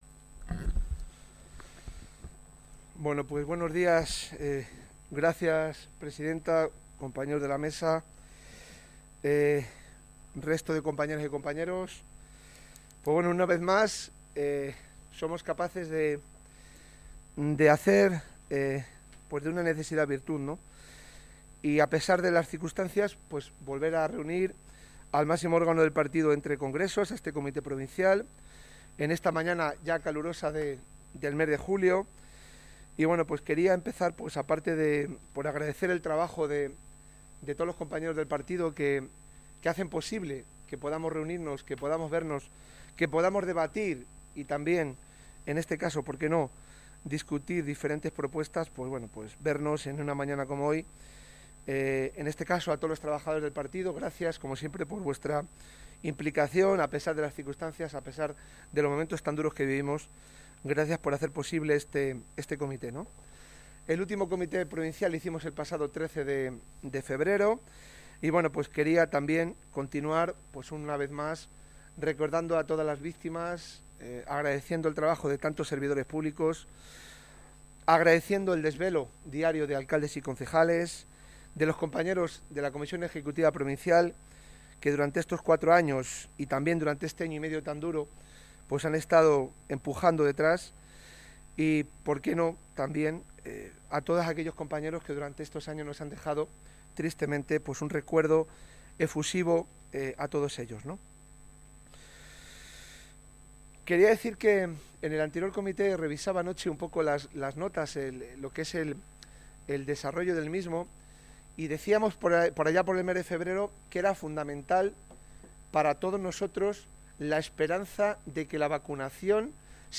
Así se lo ha anunciado esta mañana a sus compañeros de formación durante el trascurso del Comité Provincial que se ha celebrado de manera telemática.